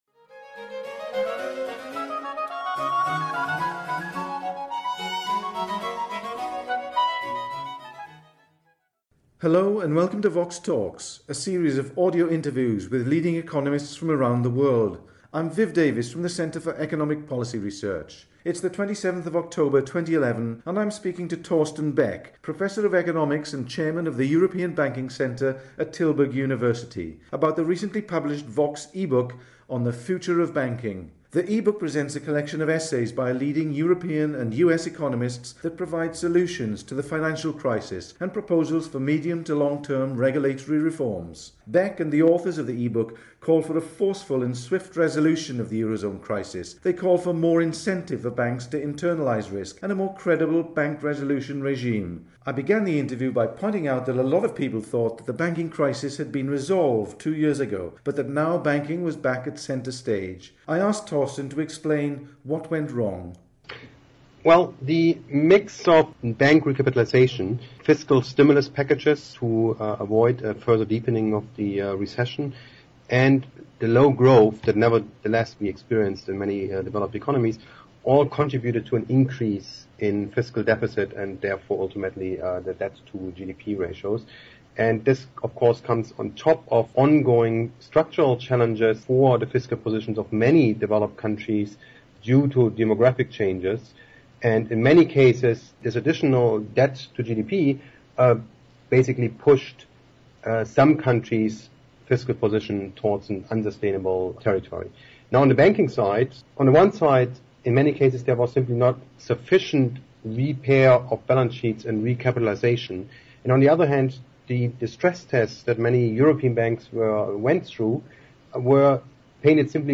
The authors call for a forceful resolution to the current crisis in the Eurozone, better incentives for banks to internalize risk and a more credible resolution regime. The interview was recorded on 27 October 2011.